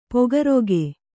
♪ pogaroge